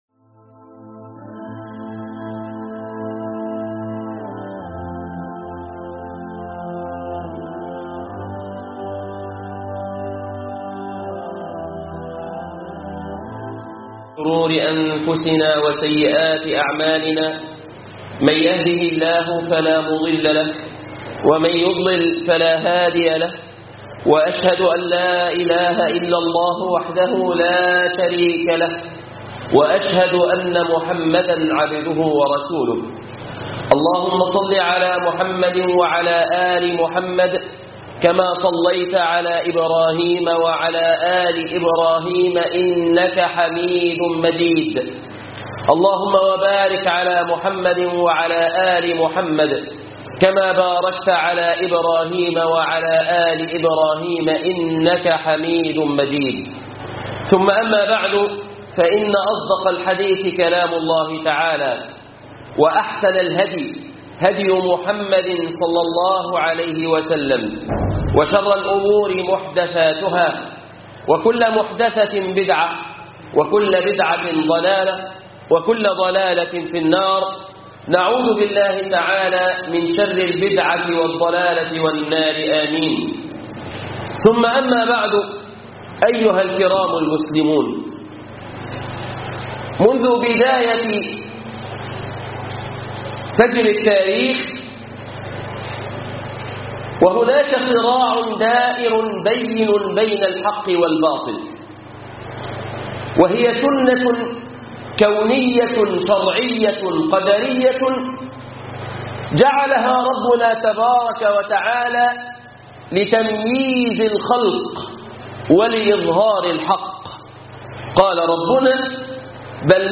خطبة الجمعة ( نبى الإسلام .. وتقارب الأديان